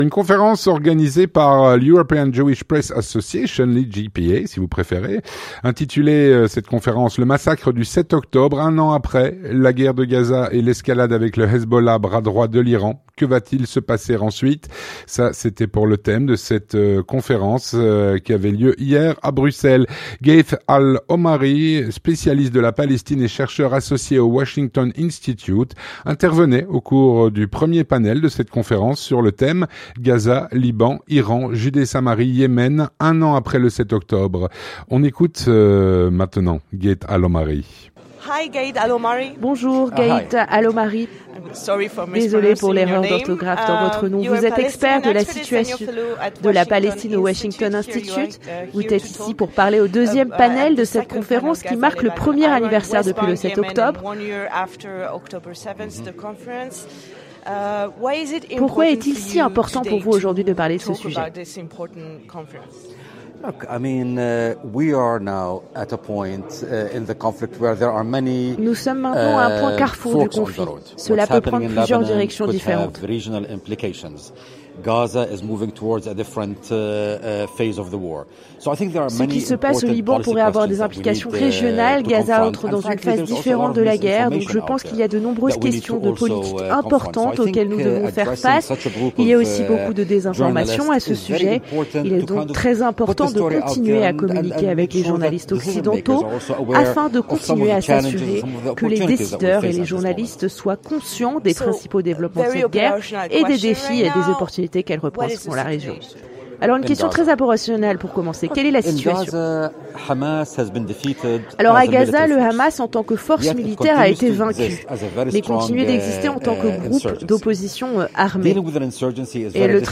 L'entretien du 18H - Une conférence organisée par l’EJPA avait lieu hier à Bruxelles.
Présenté, traduit et doublé par